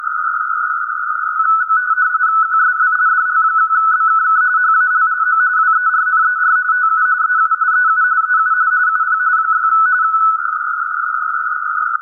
07:45z     30rtty S4, split up sur clairsemé étalé sur presque 10 kHz